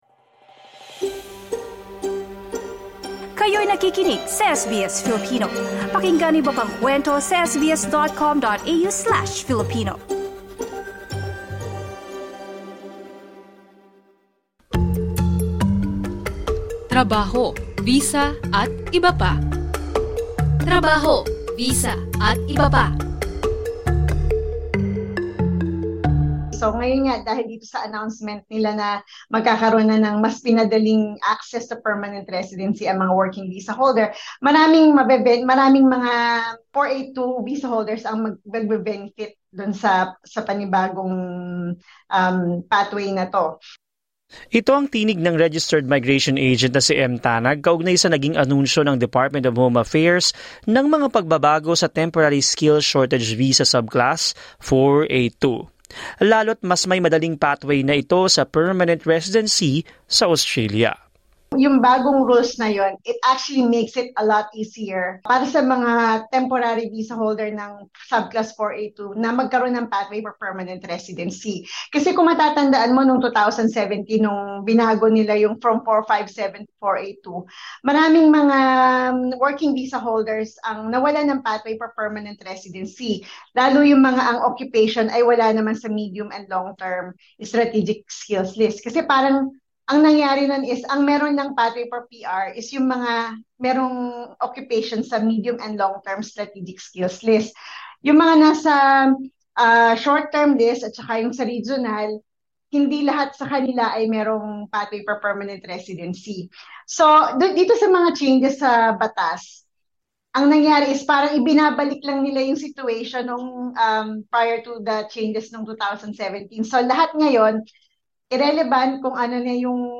In this episode of ‘Trabaho, Visa, atbp,’ a migration consultant discussed the recent government announcements regarding the Temporary Skill Shortage Visa Subclass 482.